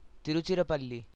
Tiruchirappalli[b] (Tamil pronunciation: [ˈt̪iɾɯtːʃiɾapːaɭːi]